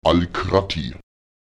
Lautsprecher al’krate [alČkrate] die Stadt, das Dorf, die Siedlung